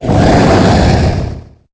Cri_0847_EB.ogg